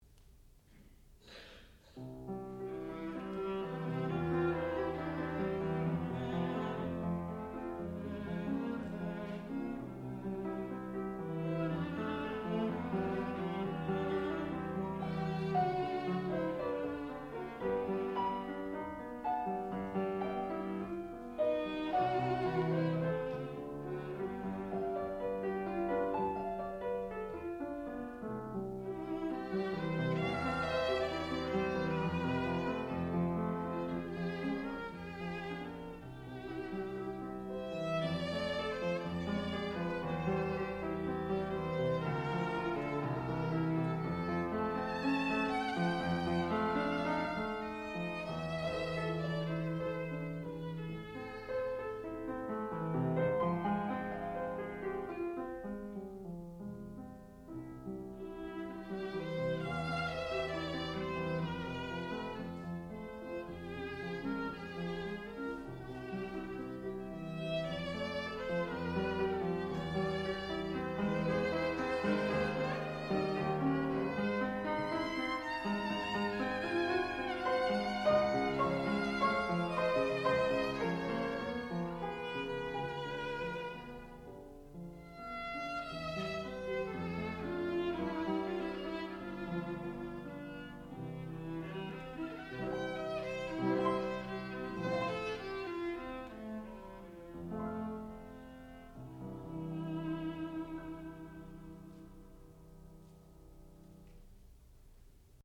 sound recording-musical
classical music
piano
viola